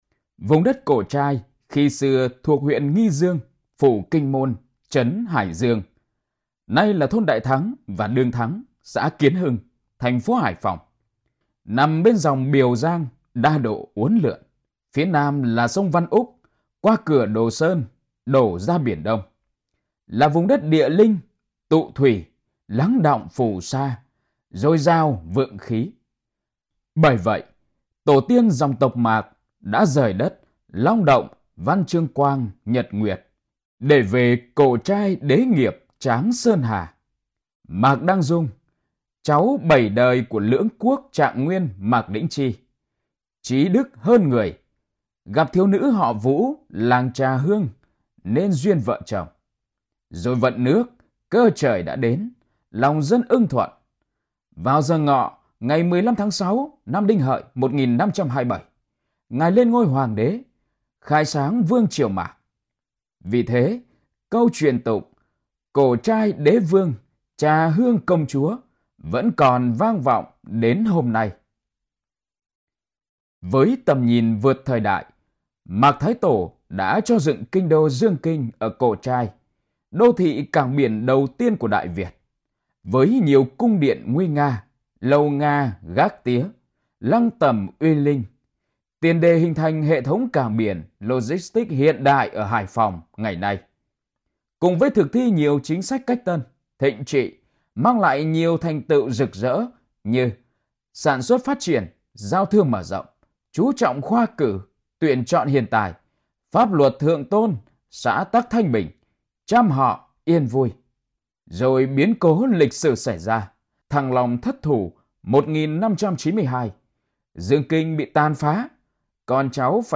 LỜI-GIỚI-THIỆU-DI-TÍCH-QGĐB-ĐỀN-THỜ-CÁC-VUA-TRIỀU-MẠC-NAM.mp3